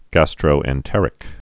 (găstrō-ĕn-tĕrĭk)